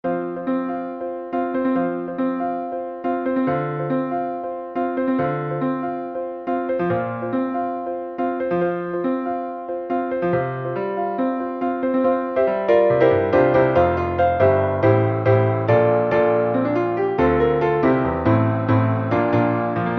piano 1 sound
The groove and harmony on this one are just amazing!